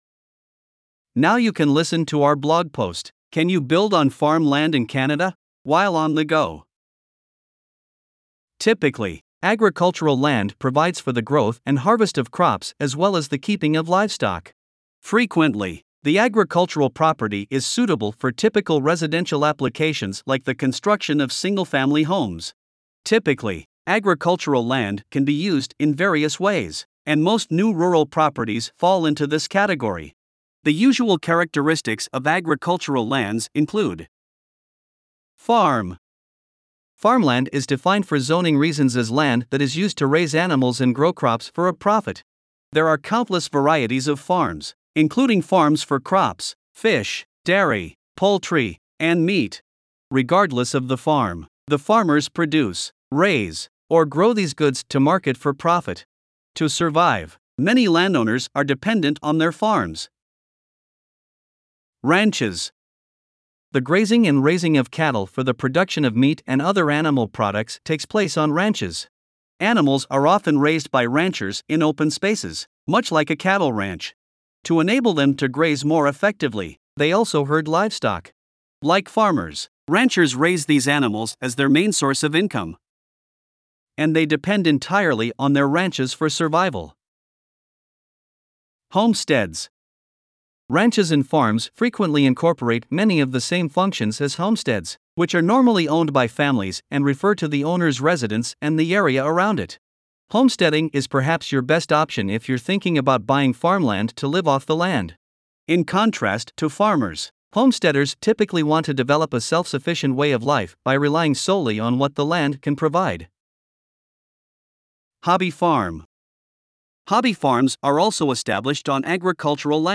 Voiceovers-Voices-by-Listnr_3.mp3